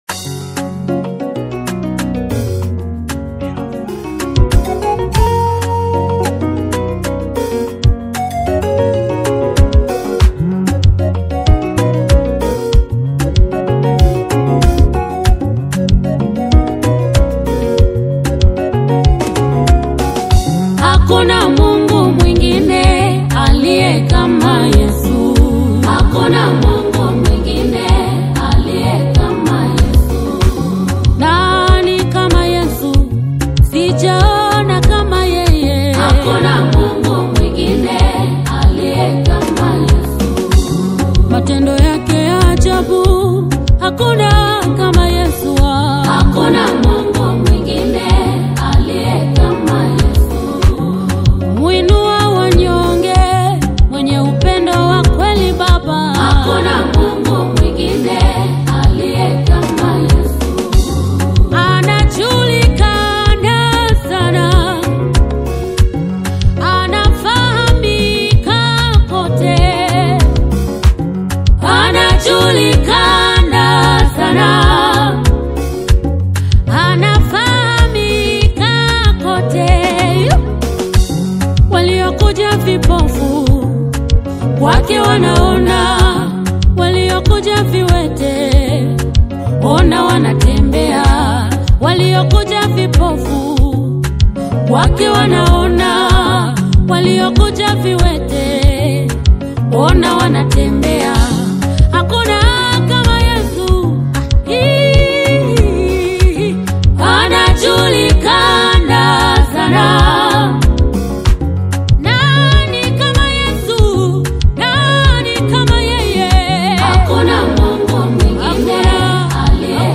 The beautifully poised and vocally commanding single